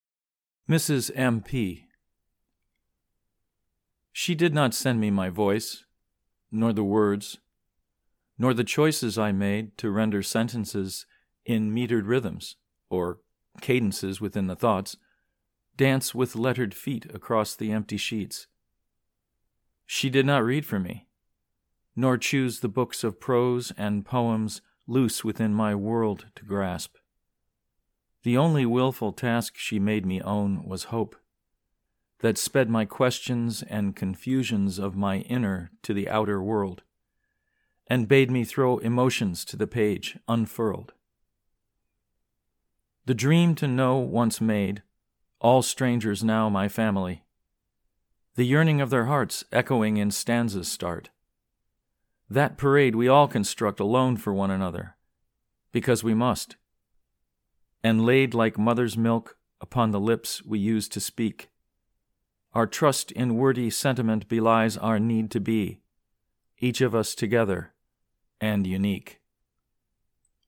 Mrs. M.P. (Recitation)